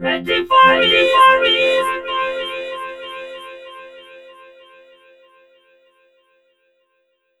READY4VOC -L.wav